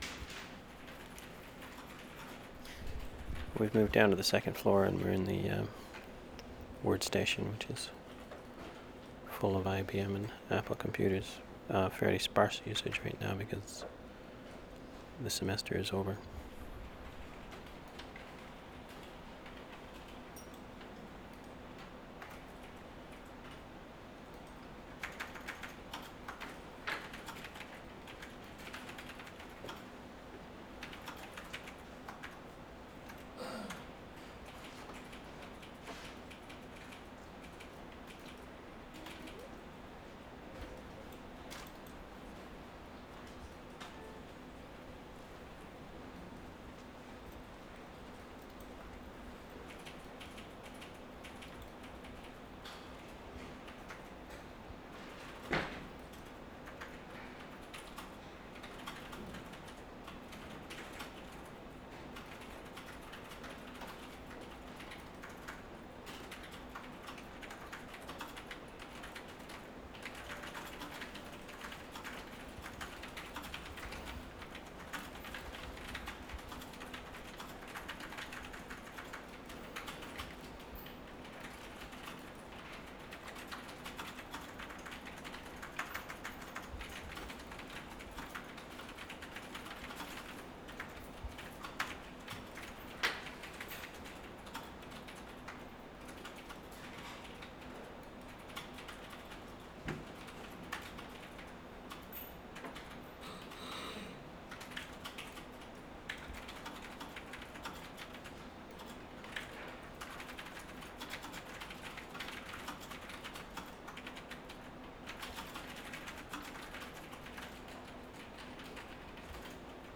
VANCOUVER SOUNDSCAPE, JUNE, 1996
library computer room 4:30
4. ID, hum of machinery and ventilation, typing, man clearing throat. Computer sounds including beeps and turn-on sound. Some mic handling noise. 1:44, man clearing throat. Door opening. 3:31, elevator door opens and then closes, 3:46 closer to fast typist, tape ID at 4:20